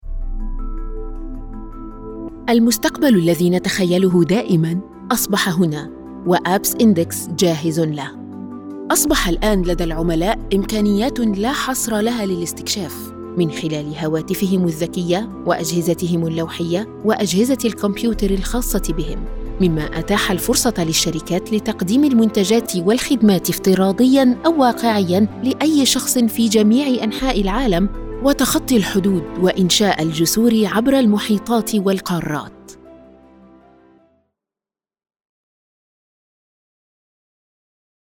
Female
Gaming-Immortal Powerful Queen
Presentation For A Tech Compan
Words that describe my voice are Warm, Conversational.
1106Narration-SH-Presentation-MSA.mp3